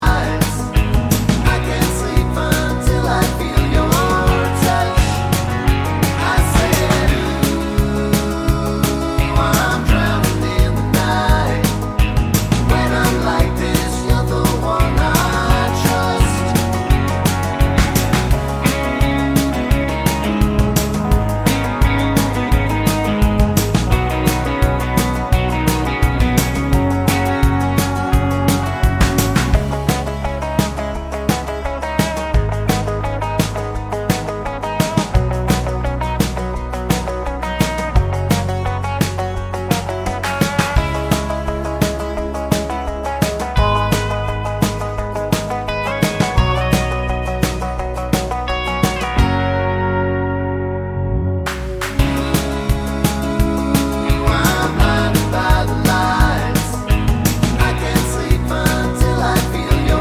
Country Version with No Backing Vocals Country